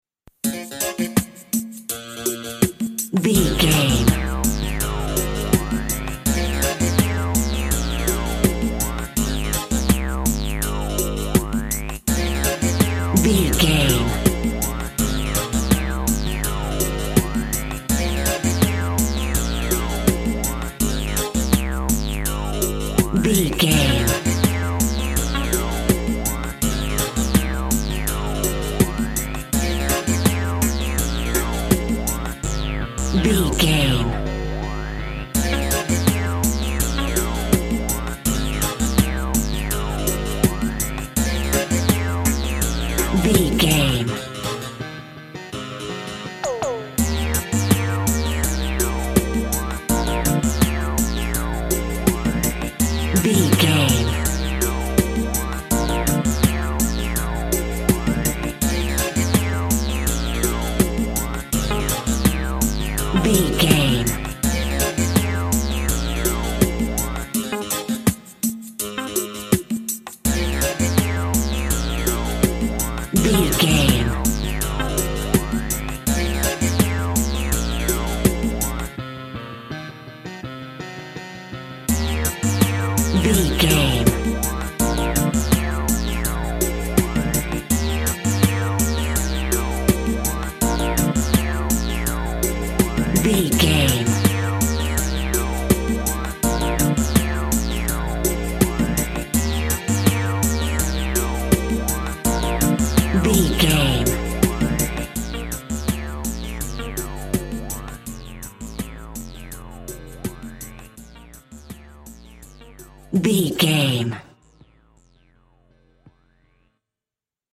Also with small elements of Dub and Rasta music.
Ionian/Major
D♭
reggae
drums
bass
guitar
piano
brass
steel drum